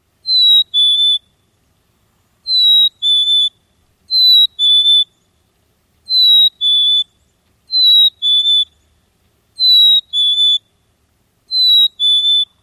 黑顶山雀叫声